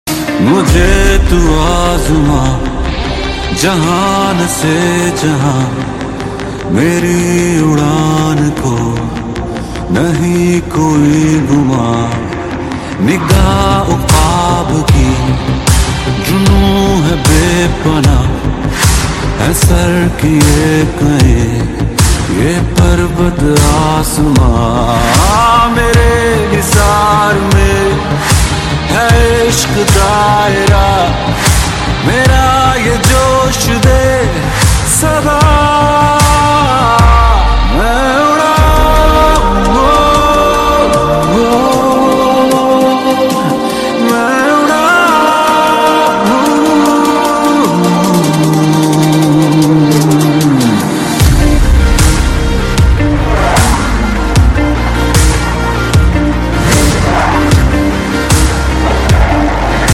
JF 17 Thunder Roars at RIAT sound effects free download